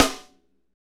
Index of /90_sSampleCDs/Northstar - Drumscapes Roland/SNR_Snares 1/SNR_Funk Snaresx